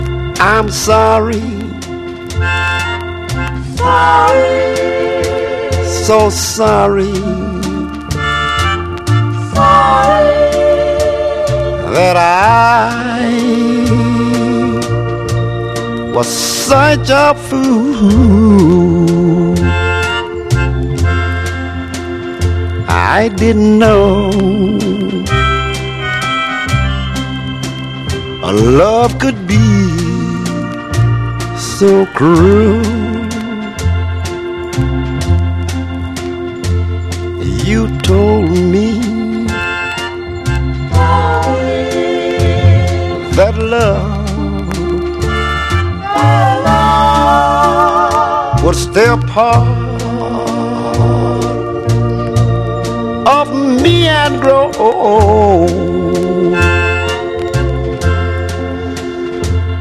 SOUL / SOUL / 60'S / RHYTHM ＆ BLUES / BLUES
タイトなホーンにギターのリフで貫き通す